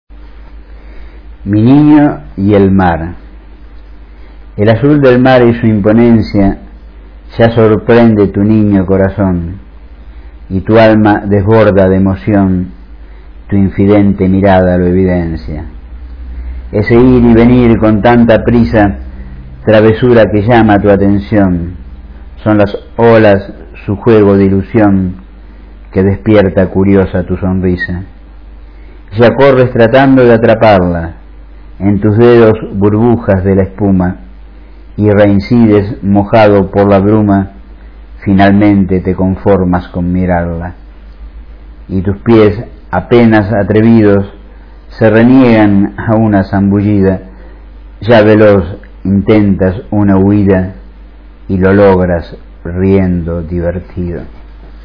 Recitado por el autor (0:54, 214 KB)